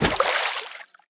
Water_splash_big_3.ogg